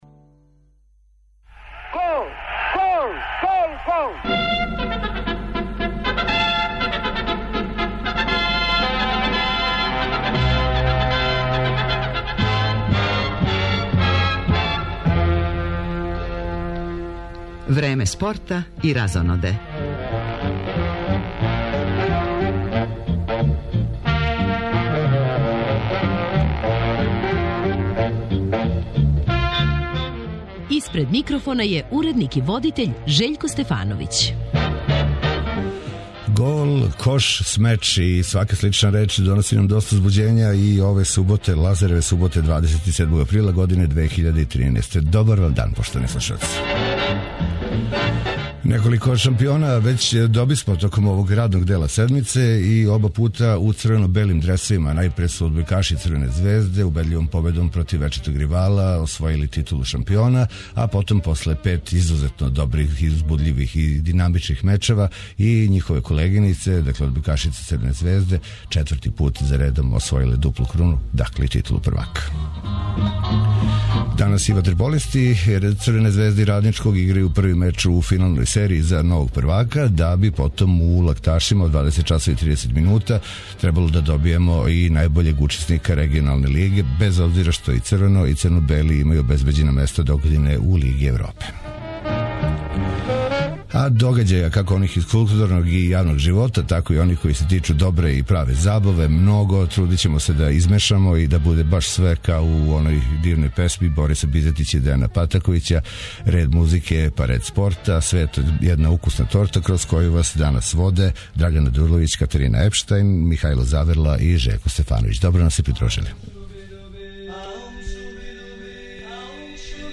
Током емисије укључиваћемо и наше репортере са утакмица Супер лиге Србије у фудбалу, као и са првог финалног меча између ватерполиста Црвене звезде и Радничког, који се игра за титулу првака. Ту је и пресек такмичења Формуле 1, после 4 одржане трке и запис са свечаног отварања спортско-тржног центра "Вождовац".